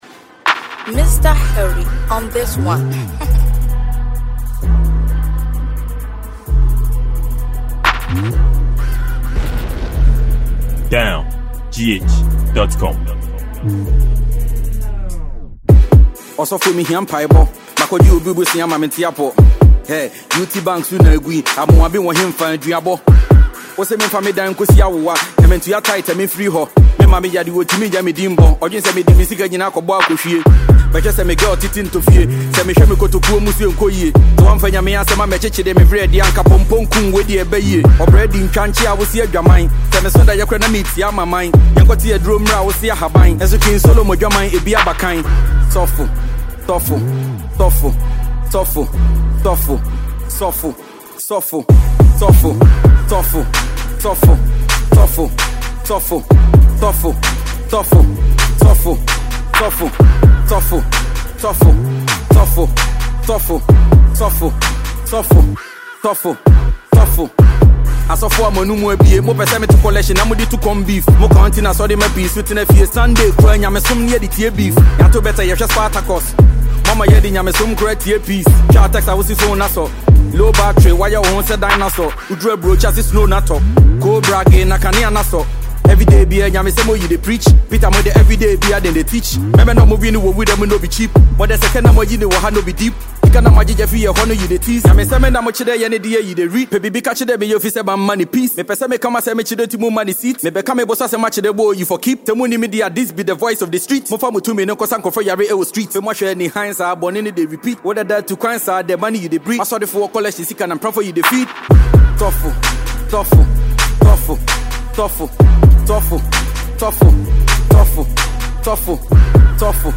Ghana Music